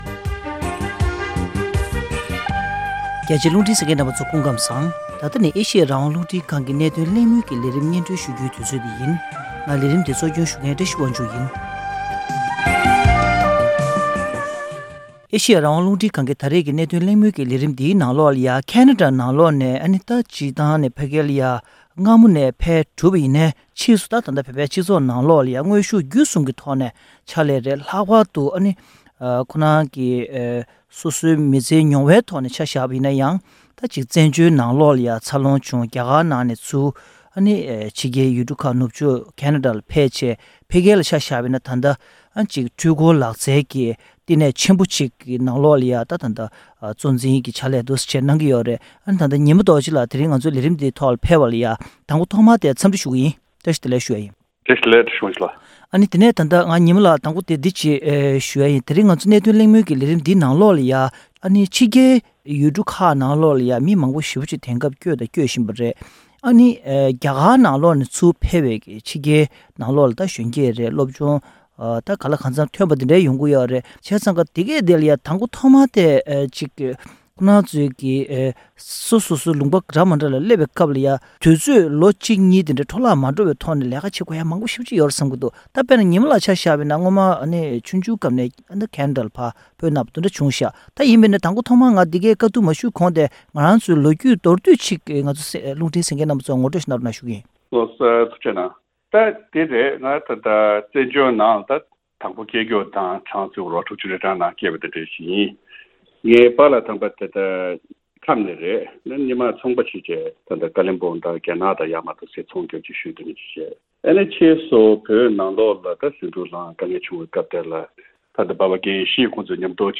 གནད་དོན་གླེང་མོལ